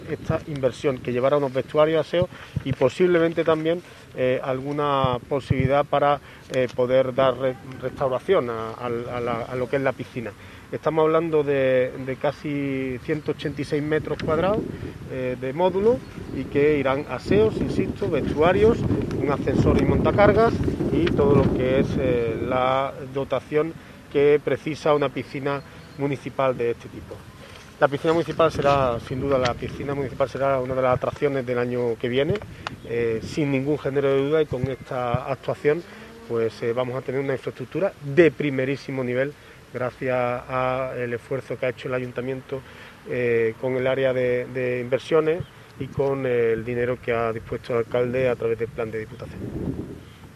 El alcalde de Antequera, Manolo Barón, y el concejal delegado de Obras, José Ramón Carmona, han anunciado el tercer proyecto que se acometerá próximamente en nuestra ciudad con cargo al Plan para la Recuperación Económica y del Empleo en la Provincia de Málaga que impulsa la Diputación Provincial.
Cortes de voz